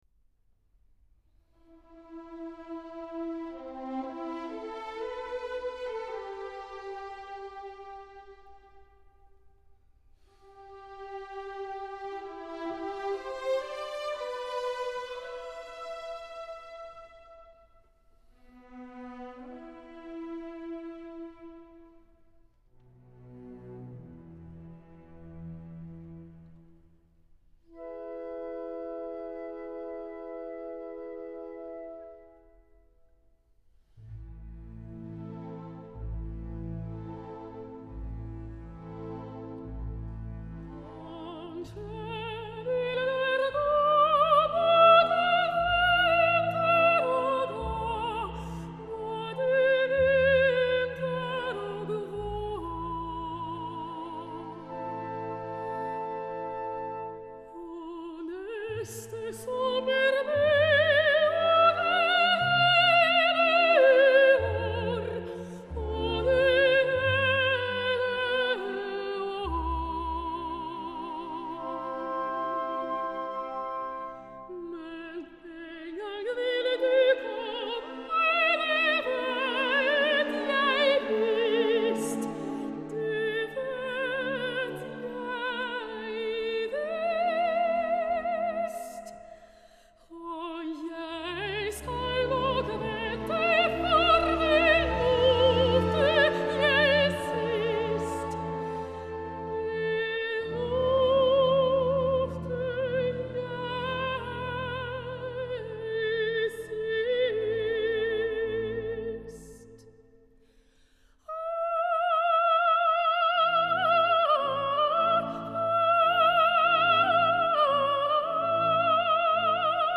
Звучить  - музика  Е. Гріга
(вик. Анна Нетребко)